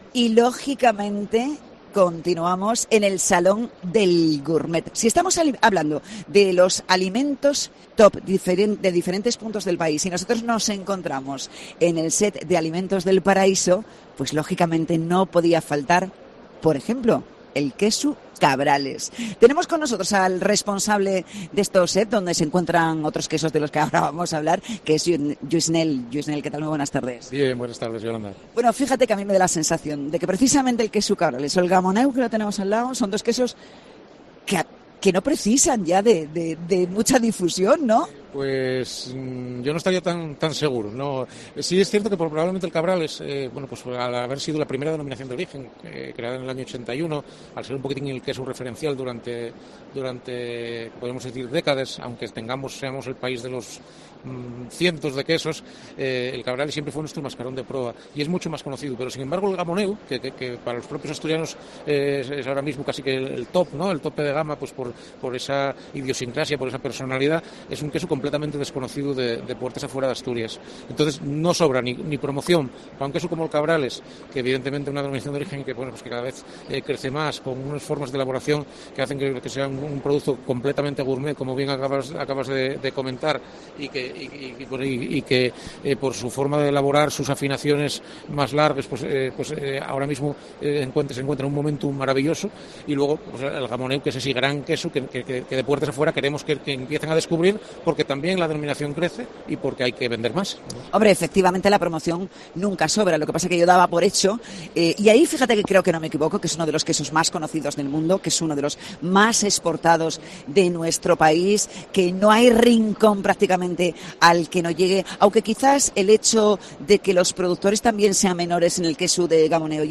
En programa especial de COPE desde el Salón Gourmets